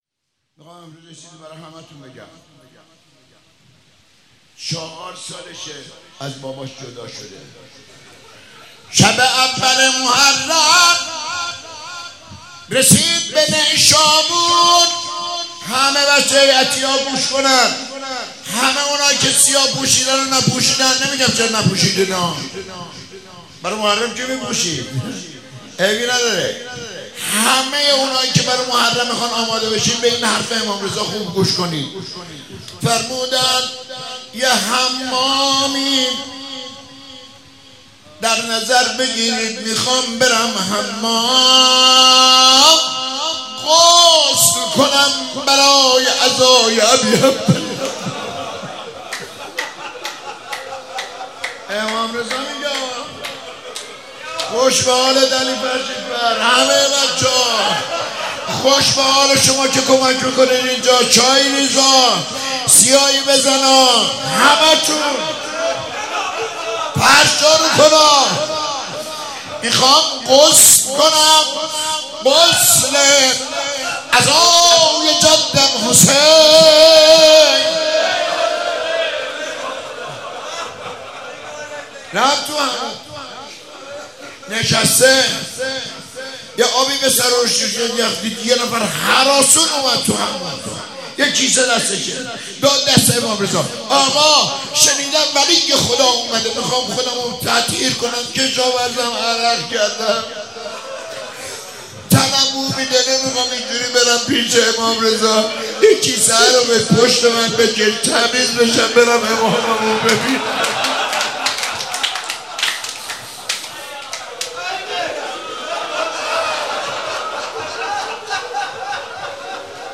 شهادت امام جواد علیه السلام96 - روضه خوانی